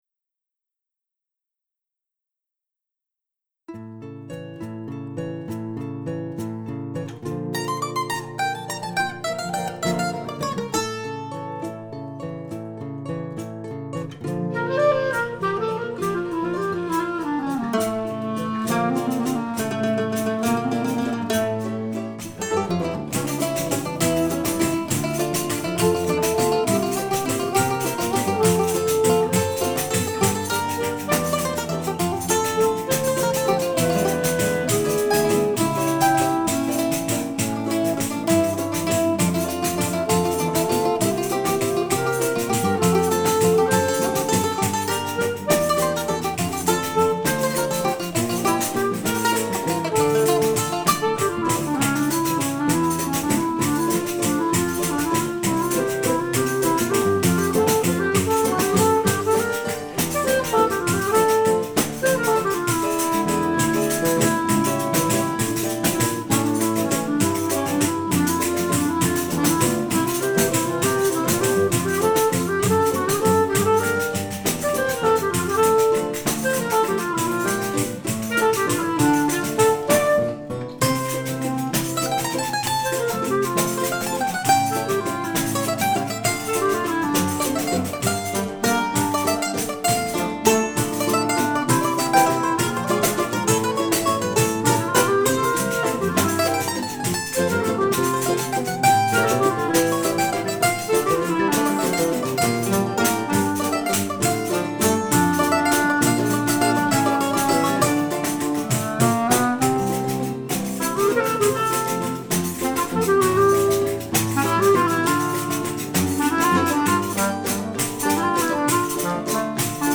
après une séance de travail Choro